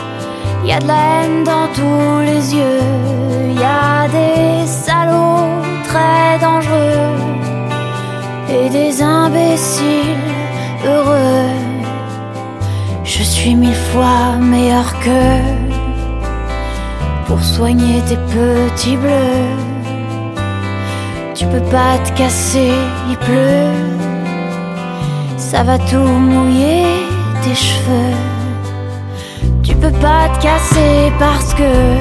voix de velours